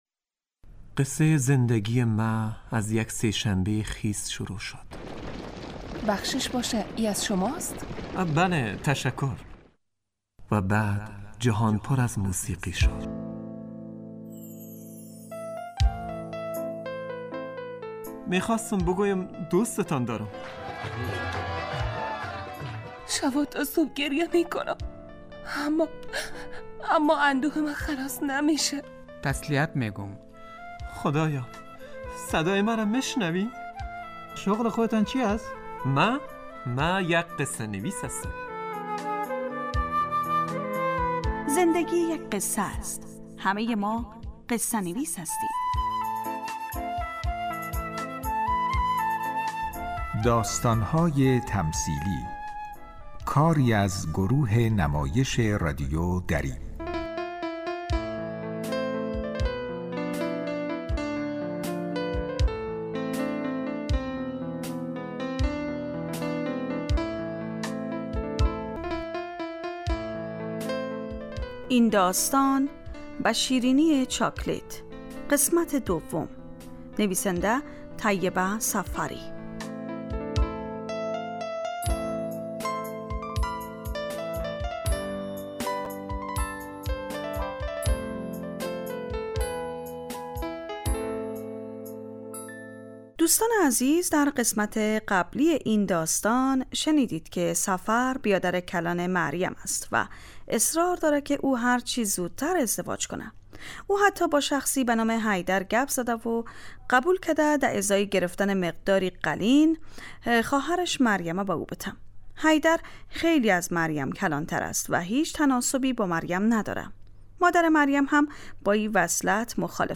داستانهای تمثیلی نمایش 15 دقیقه ای هستند که روزهای دوشنبه تا پنج شنبه ساعت 03:25عصربه وقت وافغانستان پخش می شود.